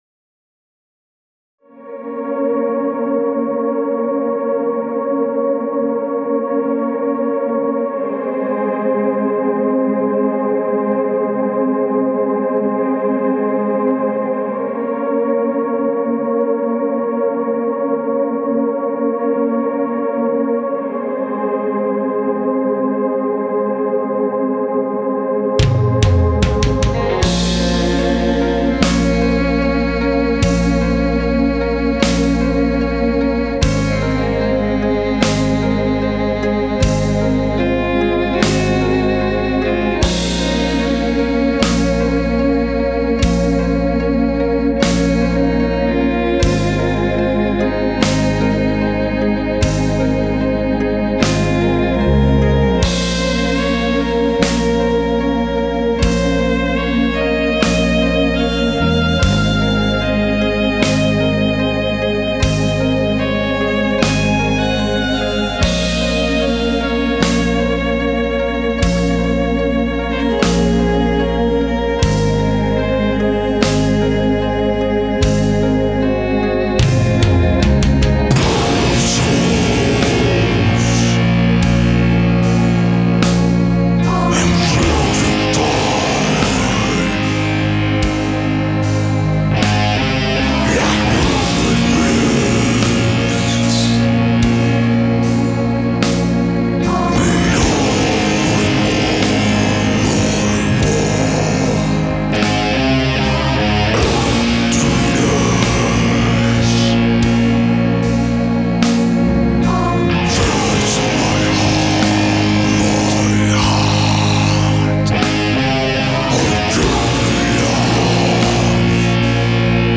Gothic Metal